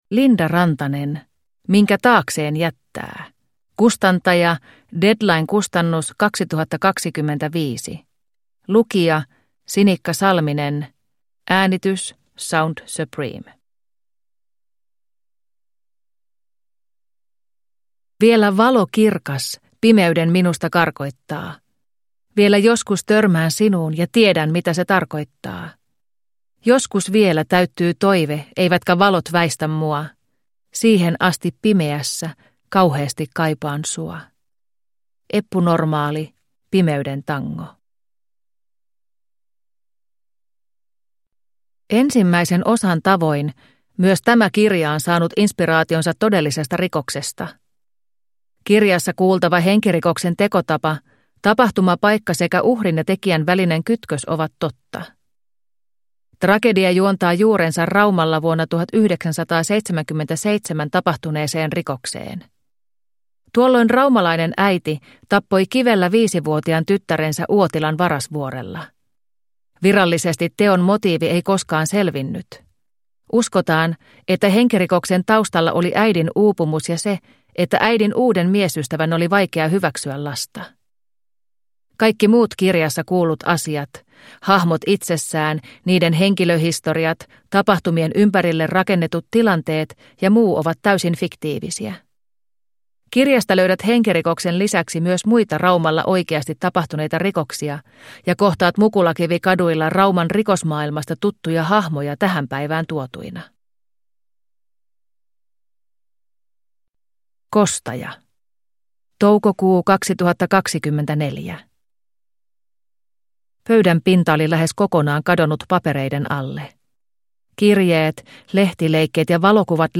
Minkä taakseen jättää (ljudbok) av Linda Rantanen